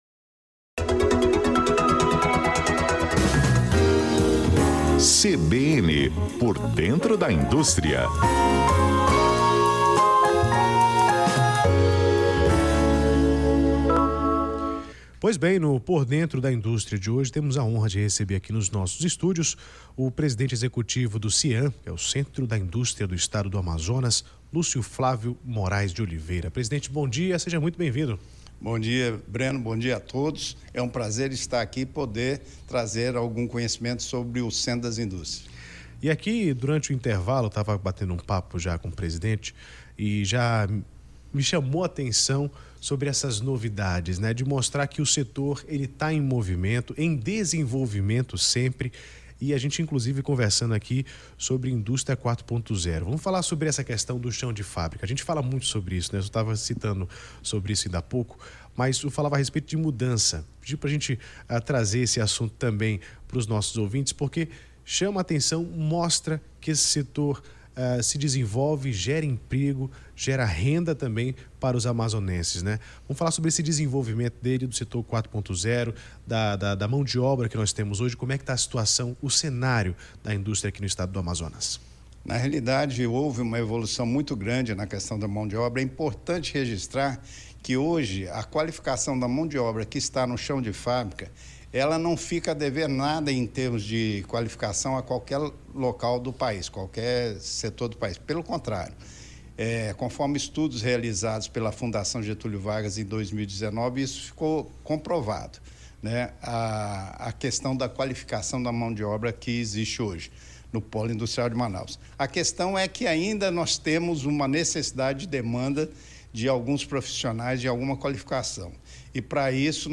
CBN Por Dentro da Indústria: entrevista